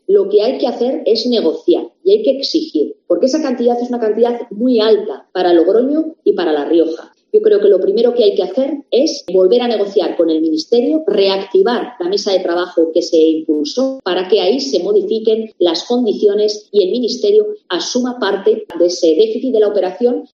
Desde Madrid, también ha participado en la rueda de prensa la exalcaldesa de Logroño y actual portavoz del PP en el Congreso, Cuca Gamarra. Ha instado al Ayuntamiento logroñés y al Gobierno riojano a negociar con el Ministerio de Transportes la modificación de las condiciones de financiación del proyecto del soterramiento para que asuma parte del déficit de la operación.
Cuca Gamarra, portavoz del PP en el Congreso